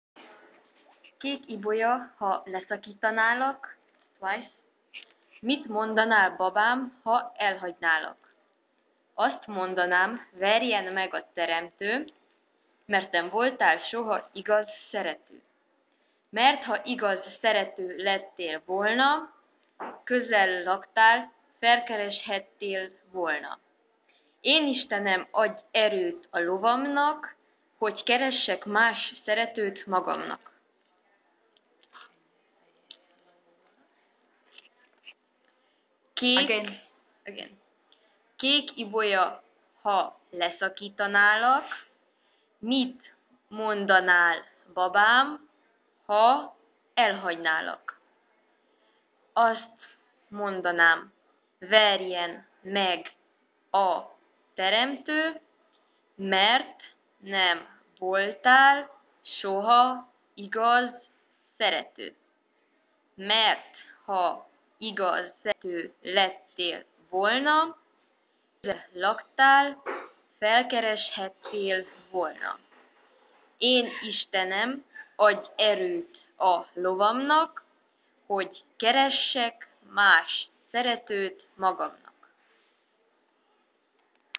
誦讀穆也納柏女子圓環歌詞(1)